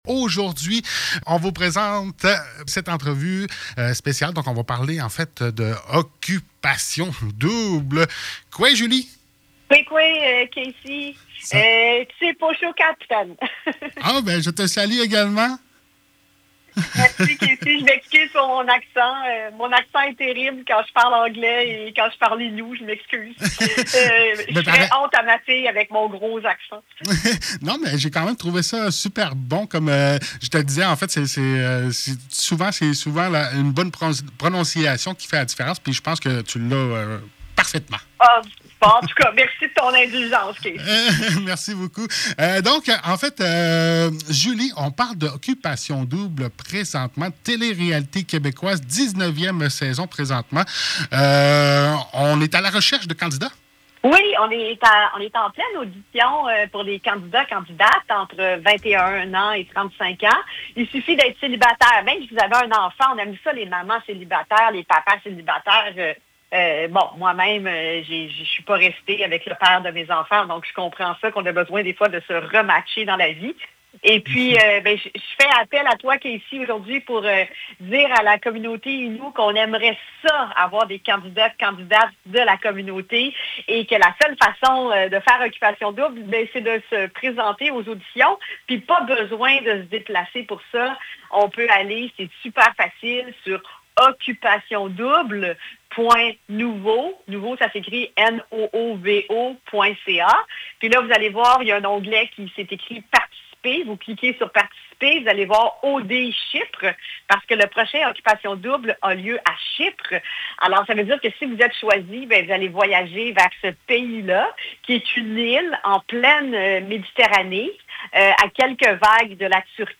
La 19e saison d’OD sera tournée cet automne à Chypre, une île de la mer Méditerranée ! En entrevue, nul autre que Julie Snyder qui vous parle de la procédure d’inscription dont la date limite est le 14 avril 23h59 .